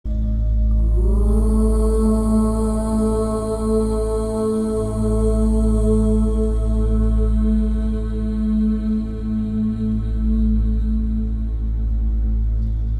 omchant.mp3